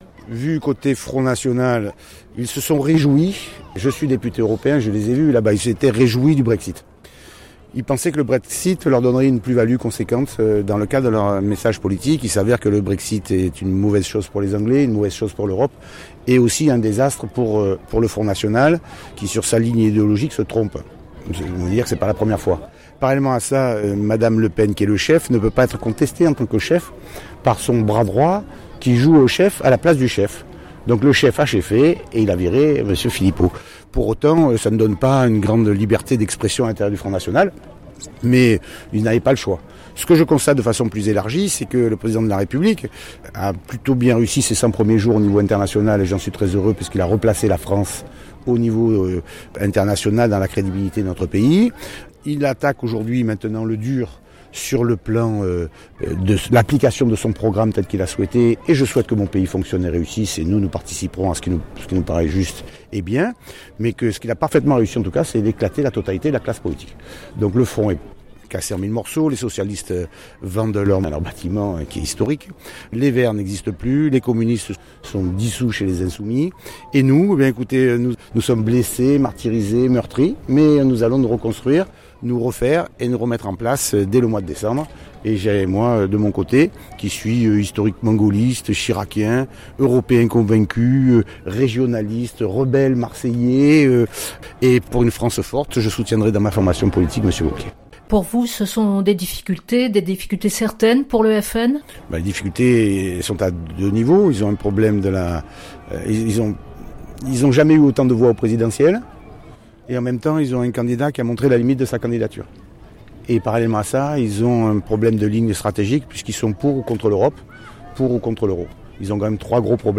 son_copie_petit-164.jpgEn marge de la visite d’Emmanuel Macron, à Marseille, le président (LR) de la Région Provence-Alpes Côte d’Azur, Renaud Muselier s’est exprimé à la fois sur le départ de Florian Philippot du FN et de manière plus élargie, il est revenu sur les 100 premiers jours du Président de la République au niveau international avant de considérer qu’au niveau national, «ce qu’il a parfaitement réussi c’est d’éclater la totalité de la classe politique»…
ste-045_muselier_reaction_philippot.mp3